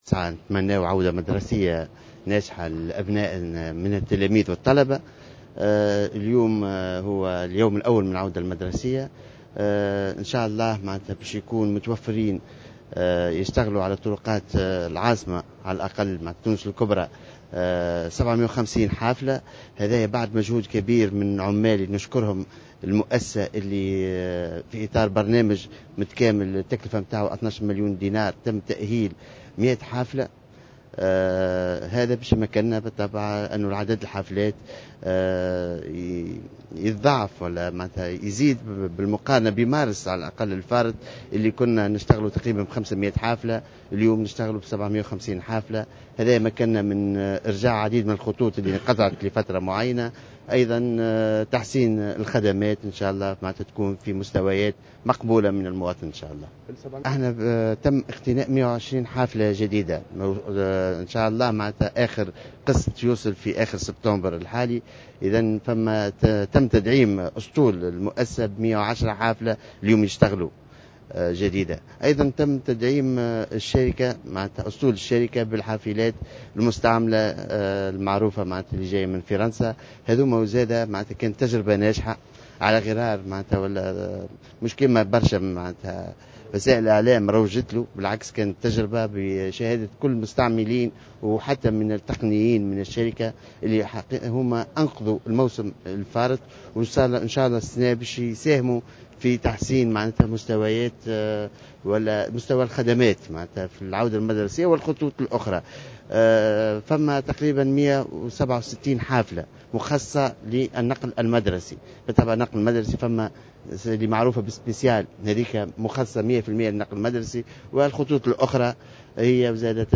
وأضاف في تصريحات صحفية أنه تم تسجيل زيادة مهمة على مستوى عدد الحافلات في تونس الكبرى بالأساس في إطار برنامج متكامل لتأهيل الحافلات بقيمة 12 مليون دينار.
يشار إلى أن تصريحات وزير النقل جاءت على هامش إشرافه صباح اليوم بمستودع البكري التابع لشركة نقل تونس، على انطلاق أسطول الحافلات بمناسبة العودة المدرسية.